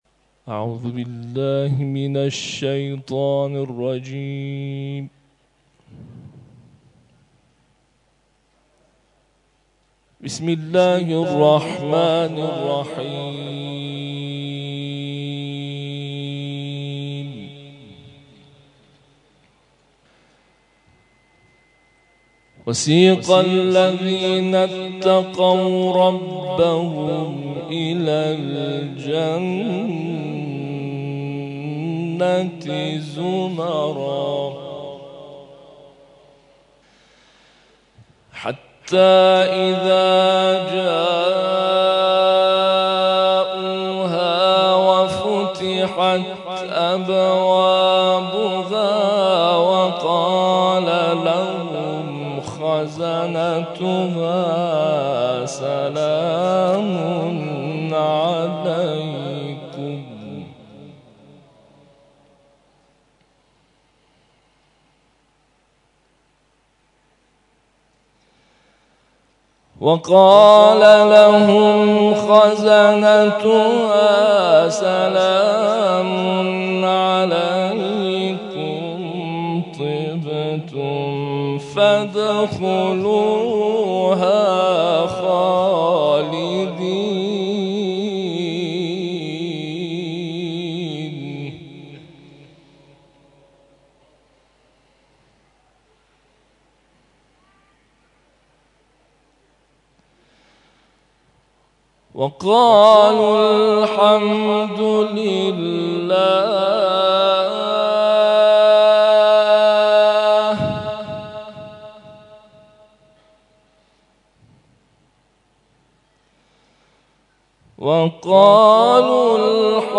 هشتادو چهارمین و هشتادو پنجمین کرسی نفحات‌القرآن
تلاوت